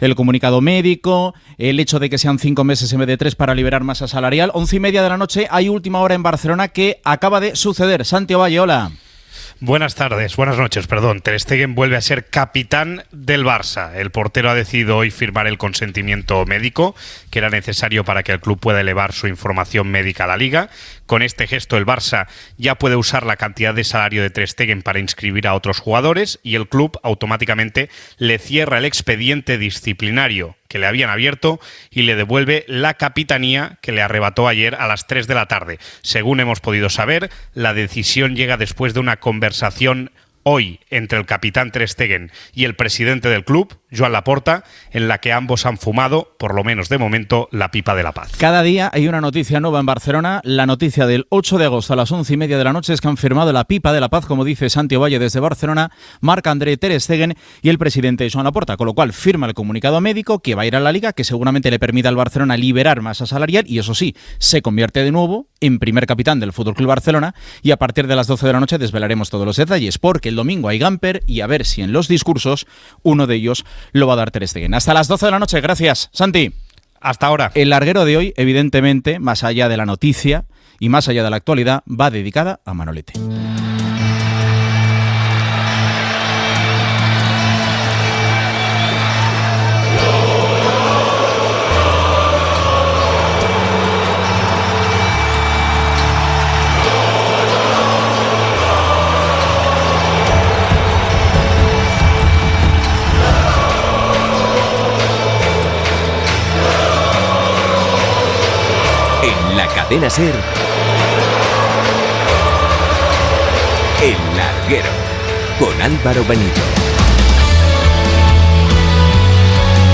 7e3019be307e7e654bbae3b247e86a00344af28f.mp3 Títol Cadena SER Emissora Ràdio Barcelona Cadena SER Titularitat Privada estatal Nom programa El larguero Descripció Informació del jugador del Futbol Club Barcelona Marc-André ter Stegen, careta del programa, record al periodista esportiu Manuel Esteban Fernández "Manolete", que va morir el 7 d'agost a l'edat dels 68 anys. Intervenció del periodista José Ramón de la Morena per valorar la seva feina a la Cadena SER i explicar alguna anècdota Gènere radiofònic Esportiu